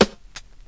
• 90s Warm Sounding Hip-Hop Snare Single Hit G# Key 55.wav
Royality free snare drum tuned to the G# note. Loudest frequency: 1765Hz
90s-warm-sounding-hip-hop-snare-single-hit-g-sharp-key-55-7RU.wav